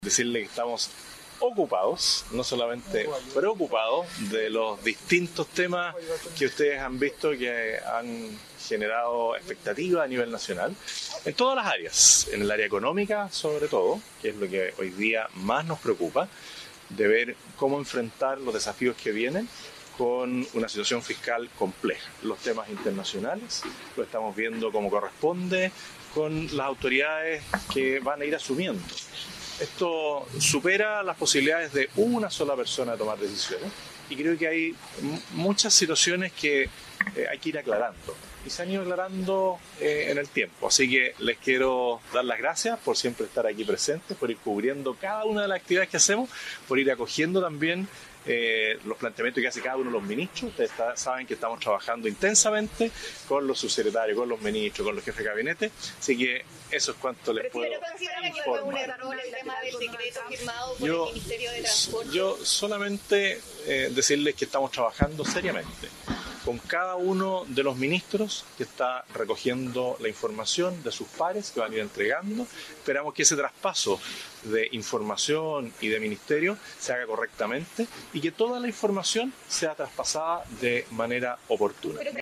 Desde las afueras de la Oficina del Presidente Electo, Kast evitó dar cuenta de medidas concretas, sin embargo, destacó que el contexto necesita una revisión institucional.